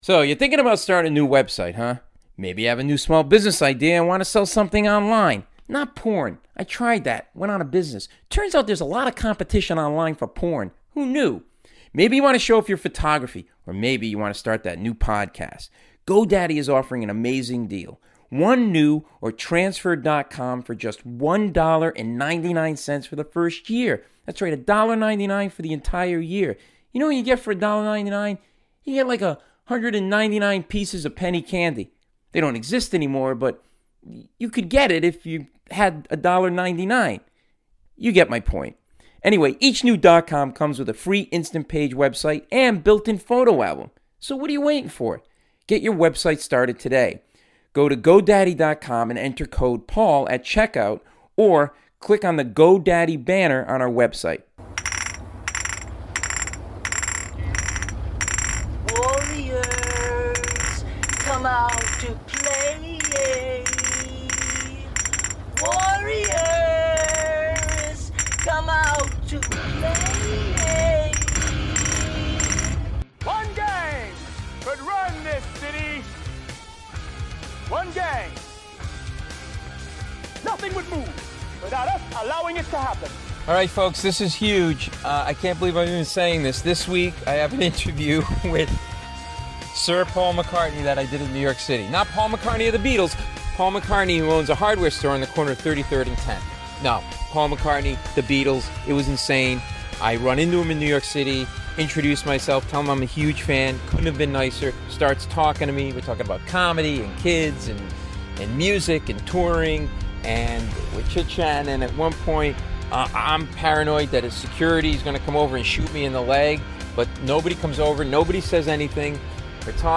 Paul McCartney: The former Beatle sat down with me for an this once in ten lifetimes, in depth interview about making music, The Beatles years, his days in Wings for a very cool conversation about everything from the making of Sgt. Pepper's to a Beatle sandwich (you'll have to listen to understand what it is).